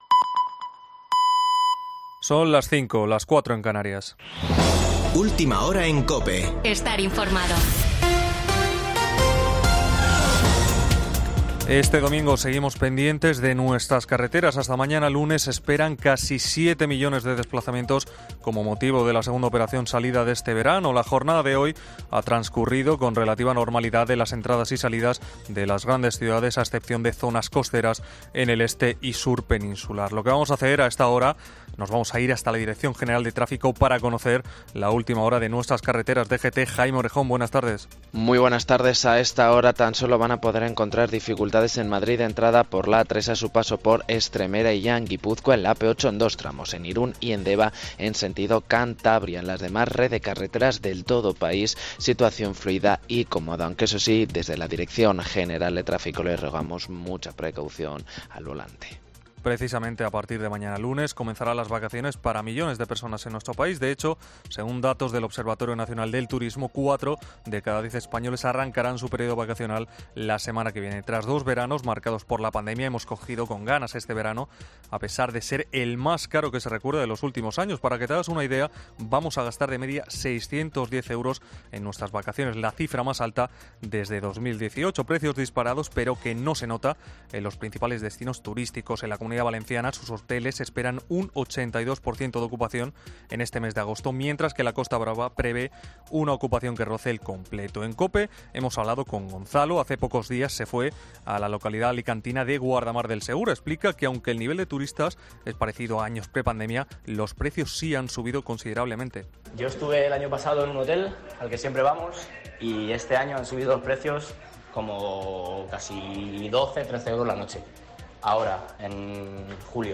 Boletín de noticias de COPE del 31 de julio de 2022 a las 17.00 horas